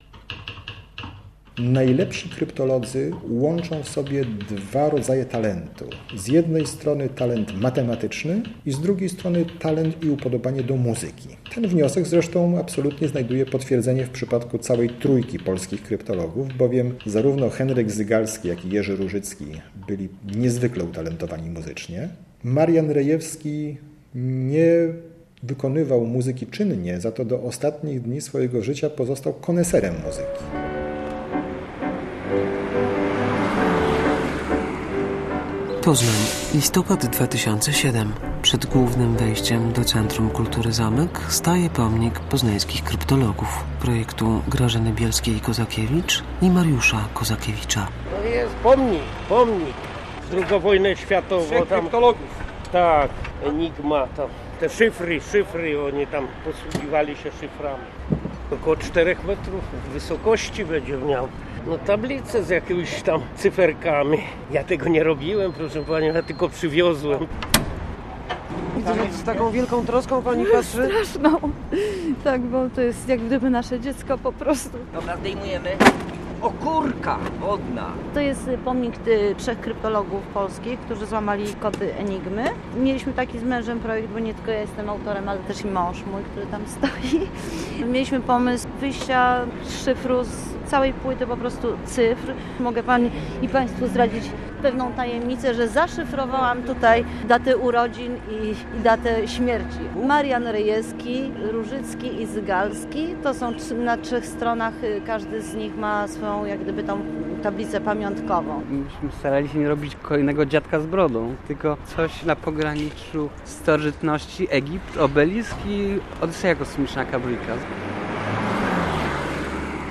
Muzeum Sukcesu - reportaż